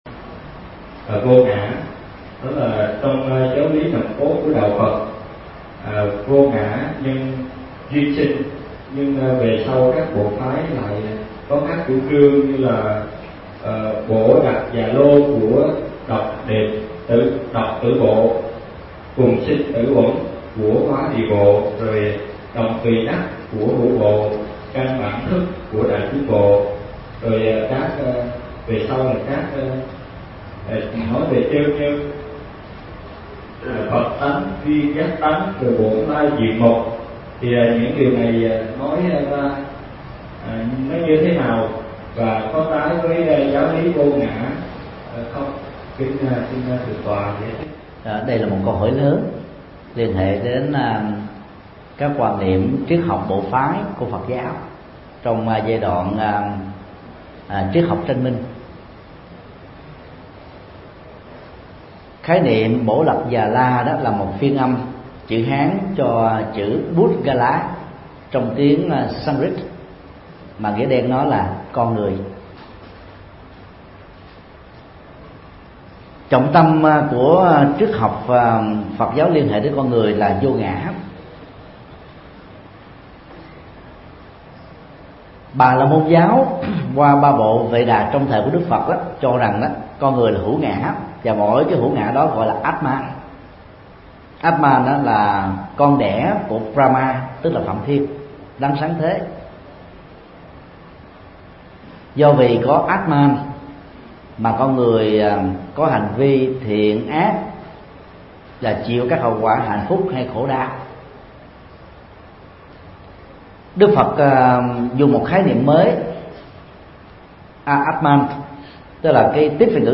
Vấn đáp: Ý nghĩa triết học vô ngã của Phật giáo – Thầy Thích Nhật Từ